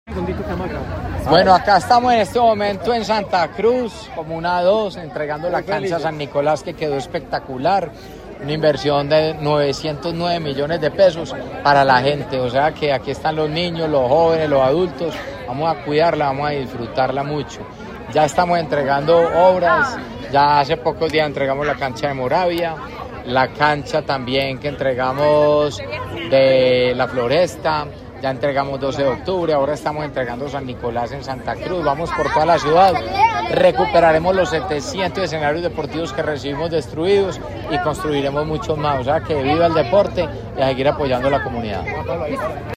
Palabras de Federico Gutiérrez Zuluaga, alcalde de Medellín